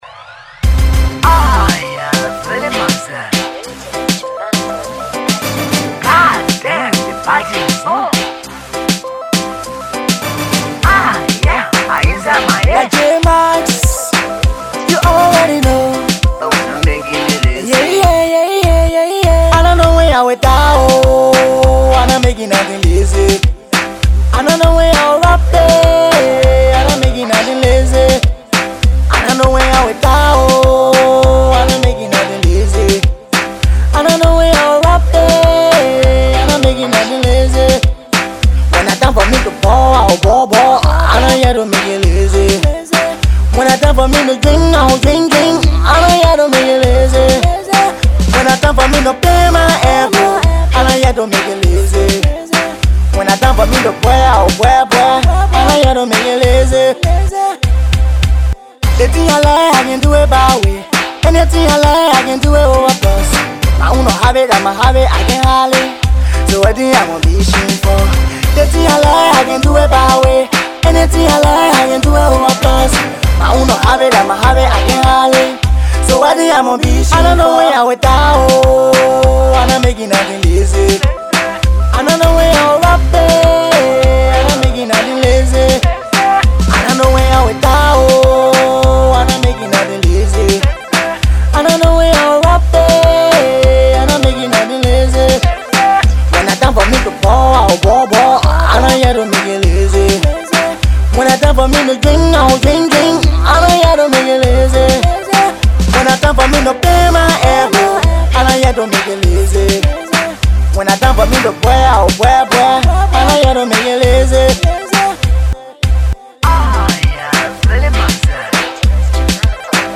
/ Dancehall / By